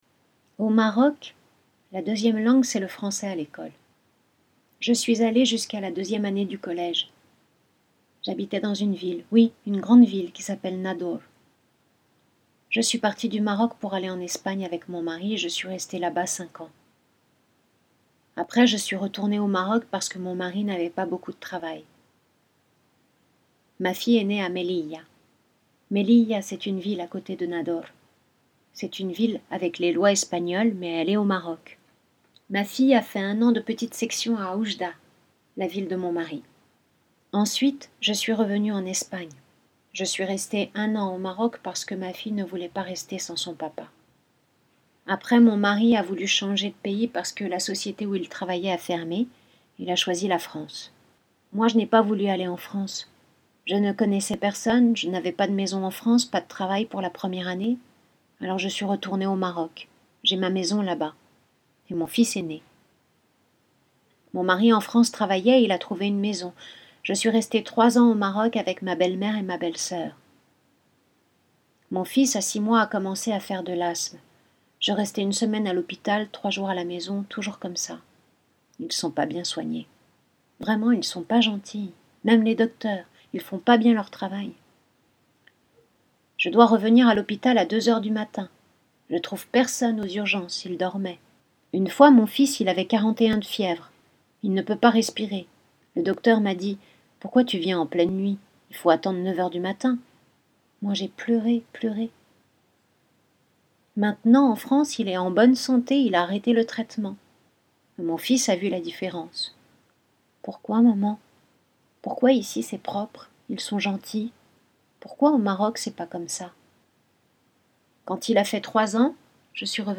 Je Vous Parle - Lecture Spectacle